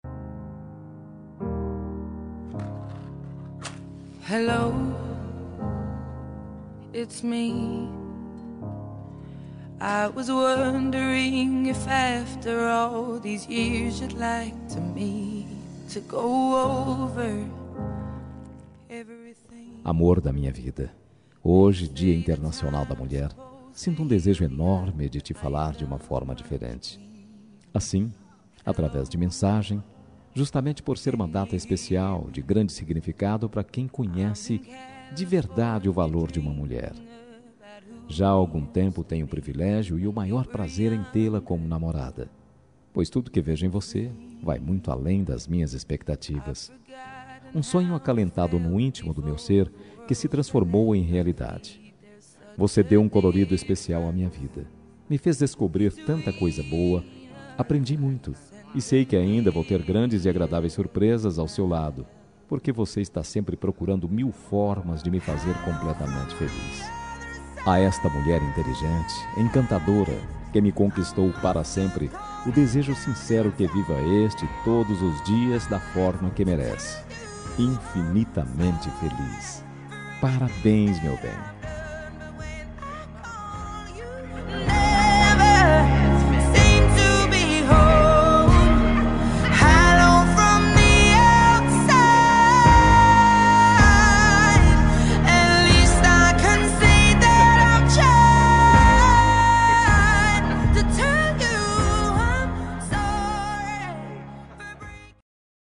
Dia das Mulheres Para Namorada – Voz Masculina – Cód: 53090